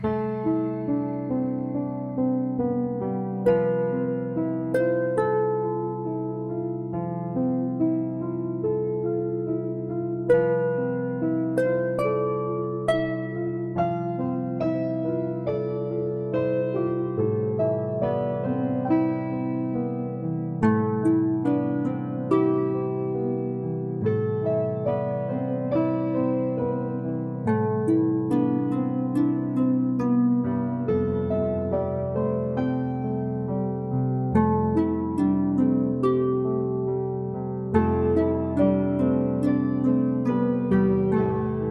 gentle uplifting music
dynamic contemporary collection